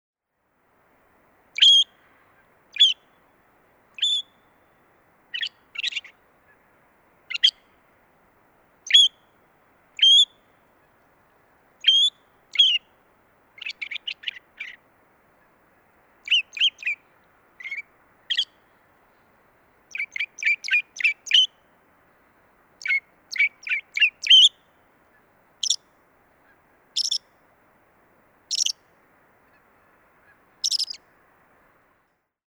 Звуки сорокопута
Звуки северного сорокопута